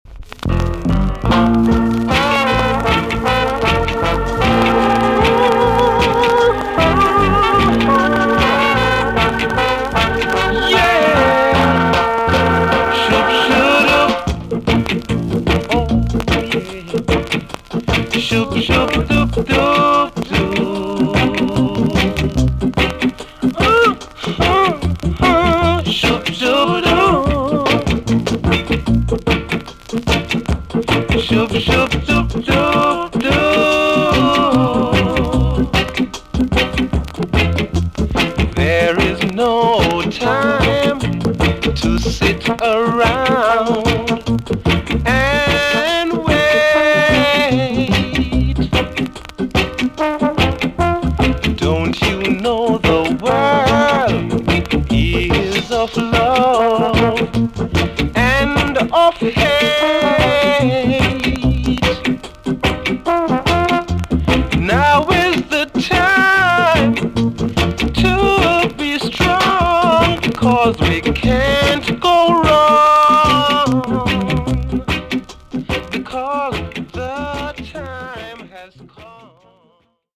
TOP >SKA & ROCKSTEADY
VG ok 全体的にチリノイズが入ります。